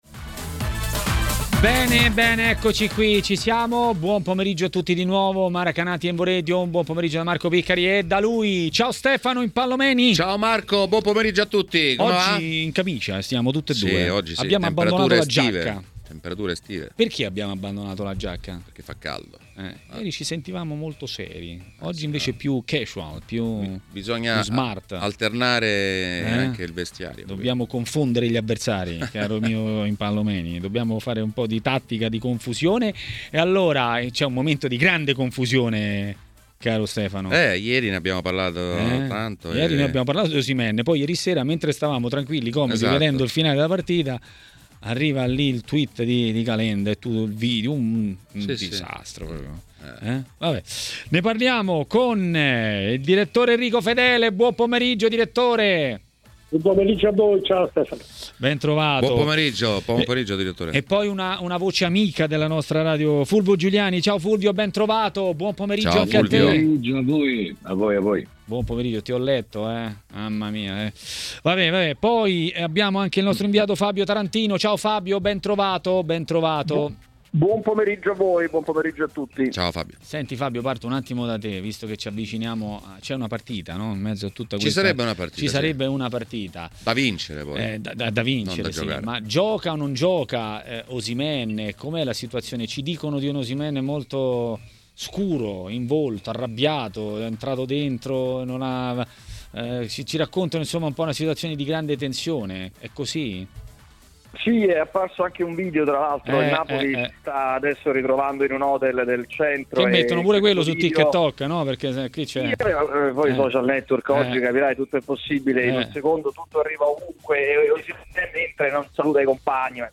nel pomeriggio di TMW Radio